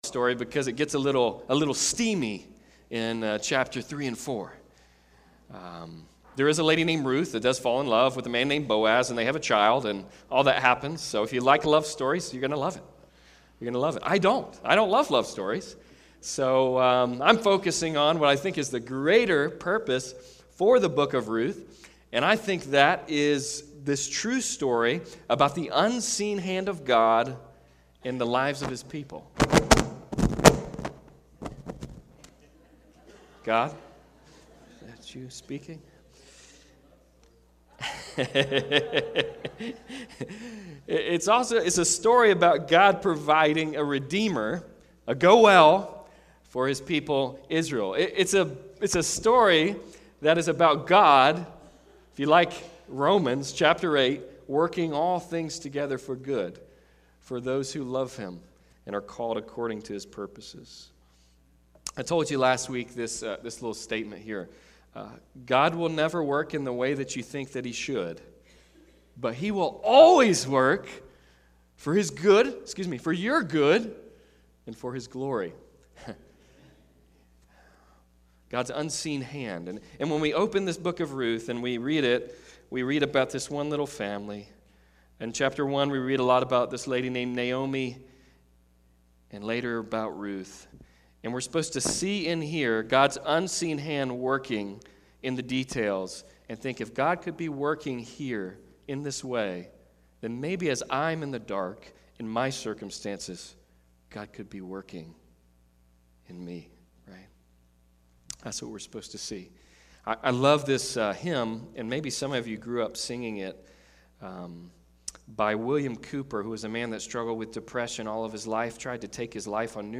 Hope in the Dark Passage: Ruth 1: 7-18 Service Type: Sunday Service « When It’s Dark